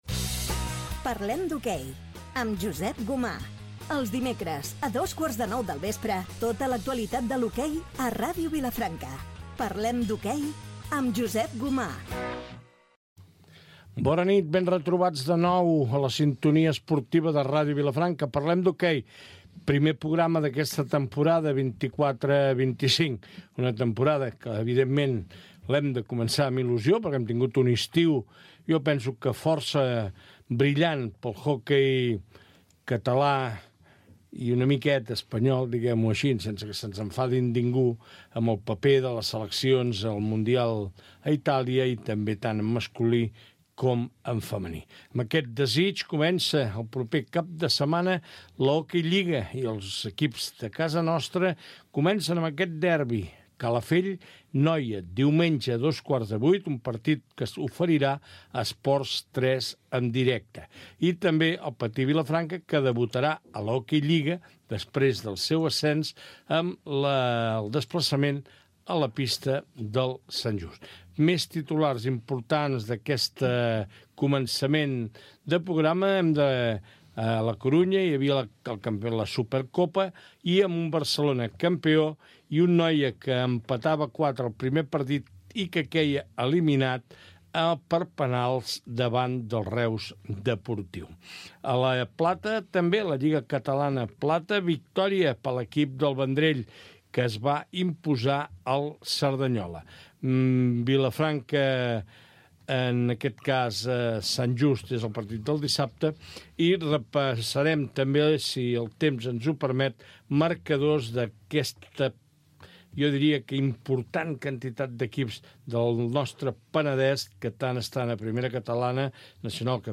Careta del programa, presentació del primer programa de la temporada. Partits de la primera jornada de l'Hoquei Lliga i repàs als últims partits disputats. Presentació de l'equip del programa, connexió amb el Pavelló de El Vendrell on es disputa un partit amistós, la pretemporada del Club Esportiu Noia Freixenet
Esportiu